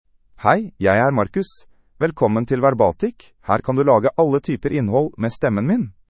Marcus — Male Norwegian Bokmål AI voice
Voice sample
Listen to Marcus's male Norwegian Bokmål voice.
Male
Marcus delivers clear pronunciation with authentic Norway Norwegian Bokmål intonation, making your content sound professionally produced.